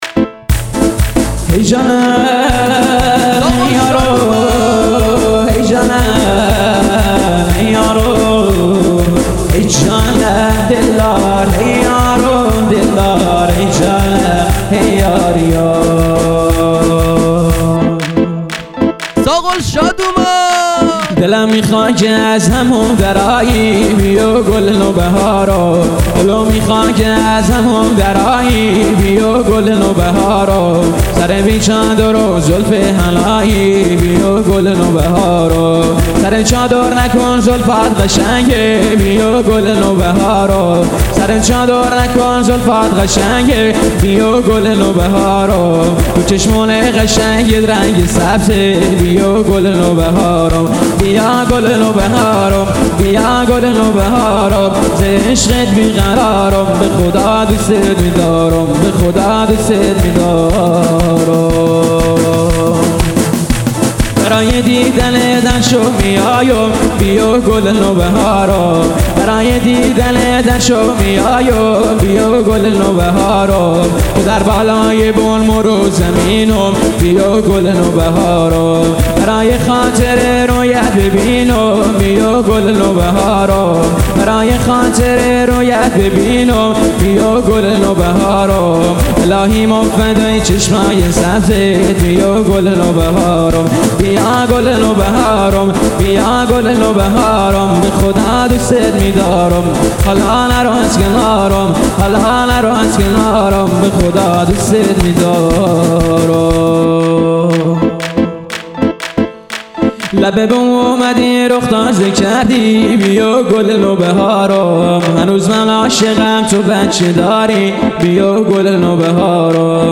دانلود آهنگ محلی شاد